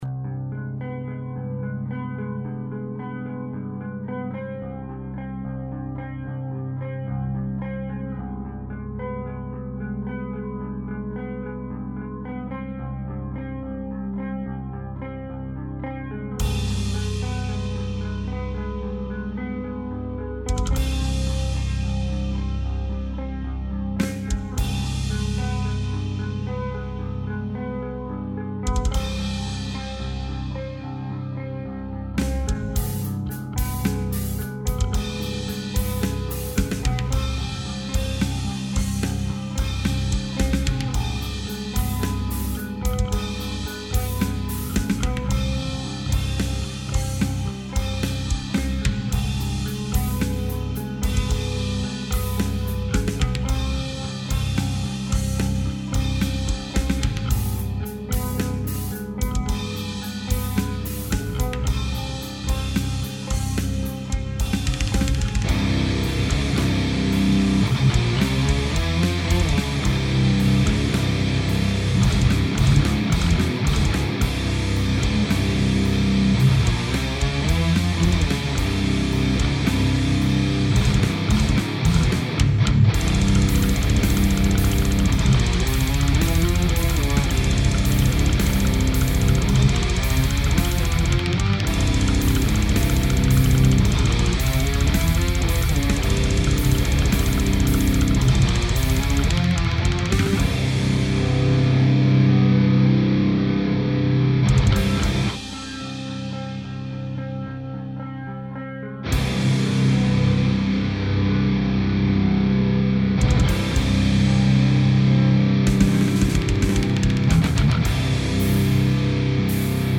Anyways, I play guitars for a Death metal band.
I never seem to get a decent Guitar sound which kind of shines out in the mix, without killing any other instrument. For this demo, I recorded 4 guitar track, 2 panned extreme and 2 panned 70-70.
The guitars and bass in the track are completely unmixed, just the drums, and the levels so that everything sonds somewhat in place and it doesn't clip at any time.